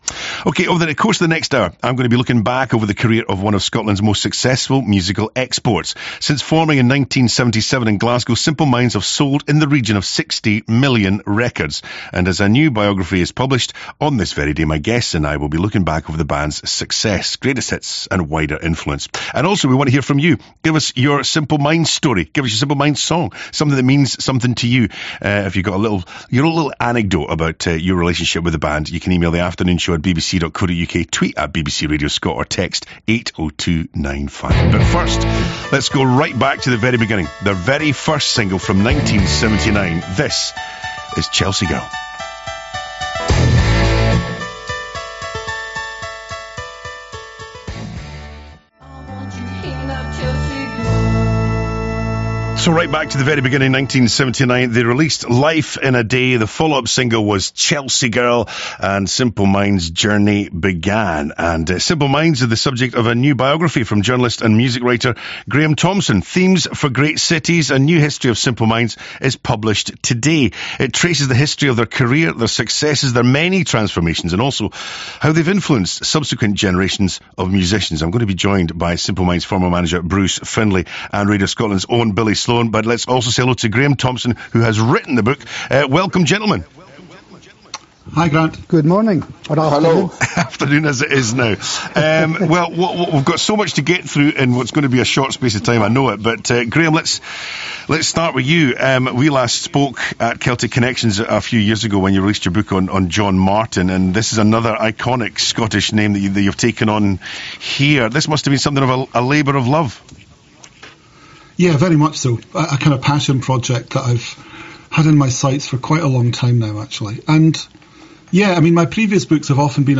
Two interviews took place almost simultaneously yesterday.
The live chat happened on BBC Radio Scotland.